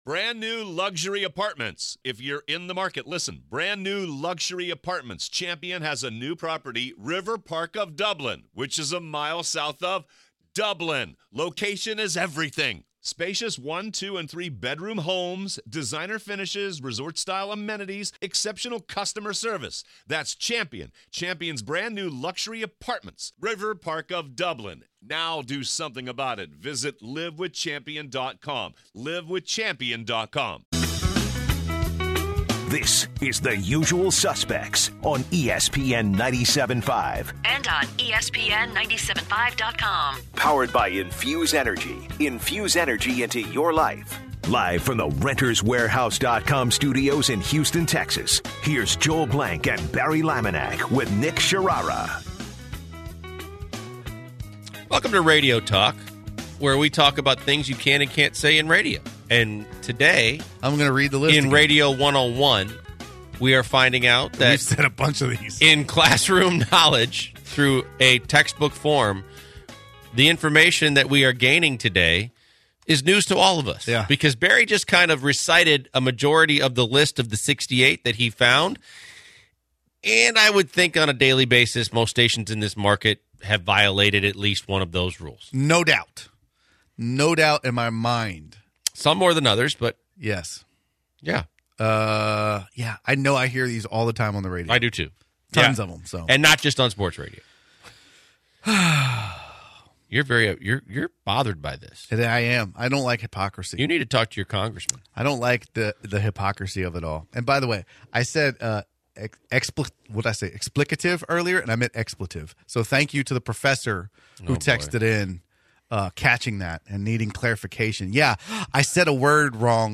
they also continue to talk about the Tournament and an angry fan calls